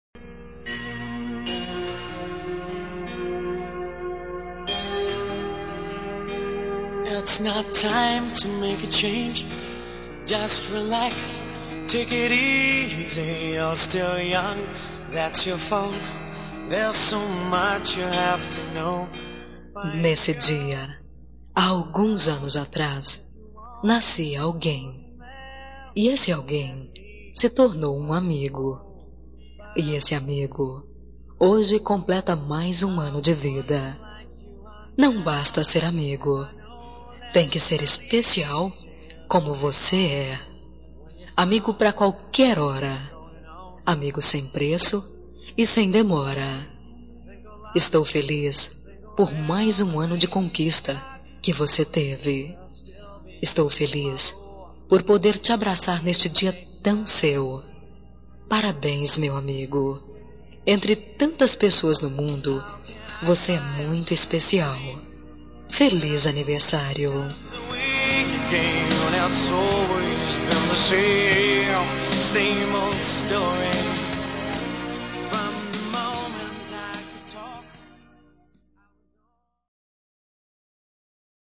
Telemensagem de Aniversário de Amigo – Voz Feminina – Cód: 1553